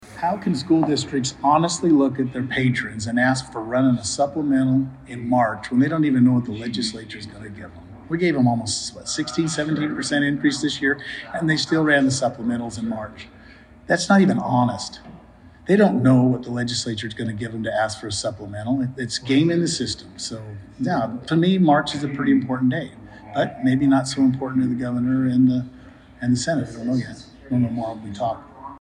House Speaker Mike Moyle reacts to Monday’s veto.